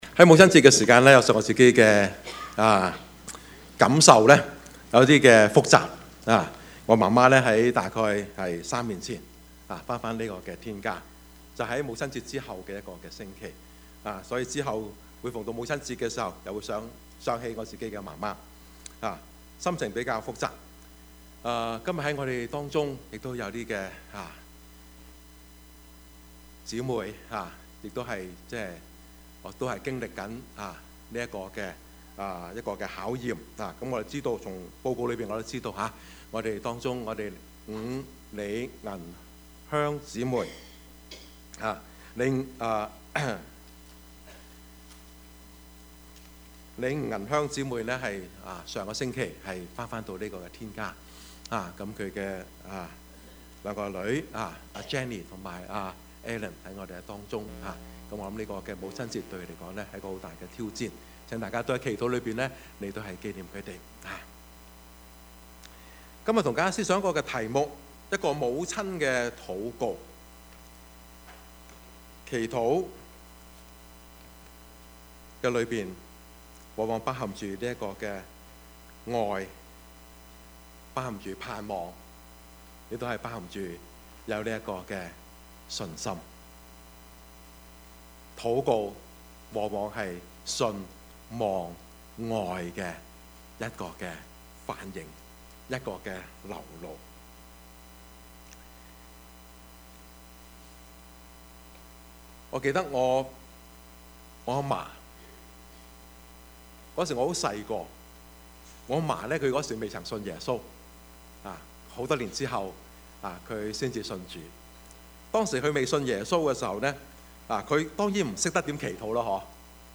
Service Type: 主日崇拜
Topics: 主日證道 « 英雄本色 團契與關懷 »